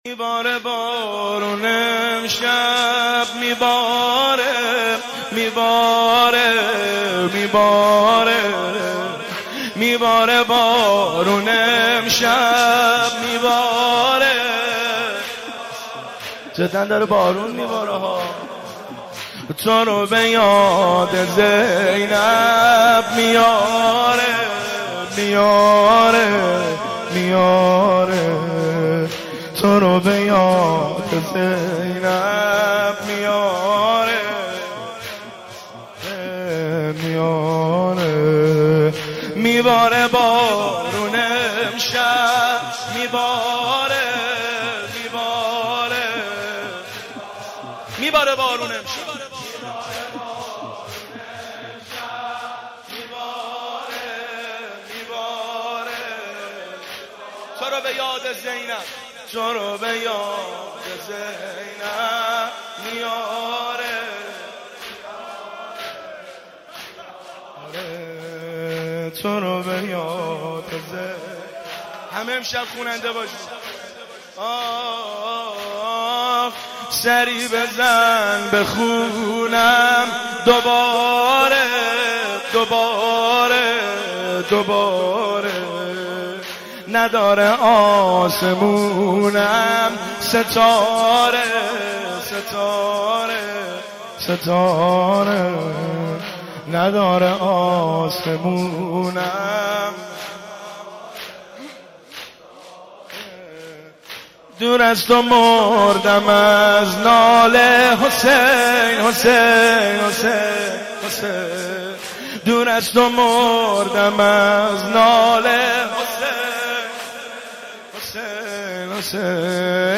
مناسبت : وفات حضرت زینب سلام‌الله‌علیها
قالب : زمینه